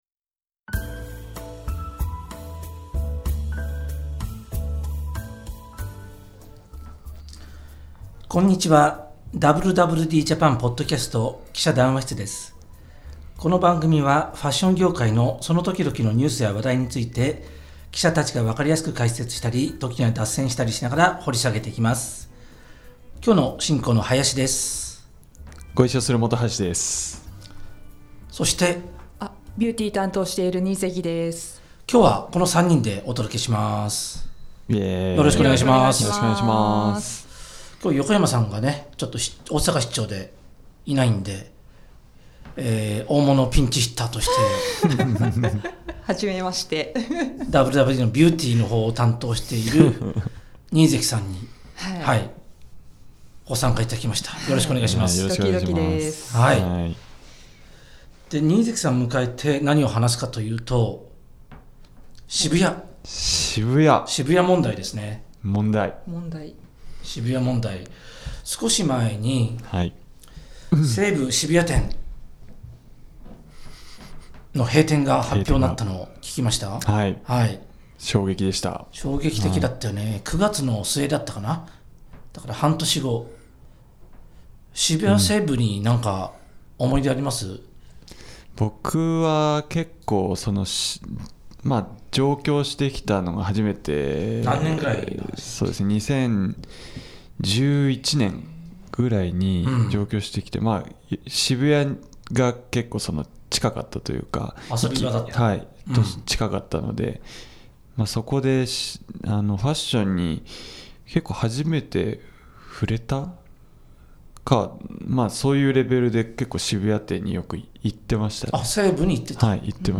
【連載 記者談話室】 「WWDJAPAN」ポッドキャストの「記者談話室」は、ファッション業界のその時々のニュースや話題について、記者たちが分かりやすく解説したり、時には脱線したりしながら、掘り下げていきます。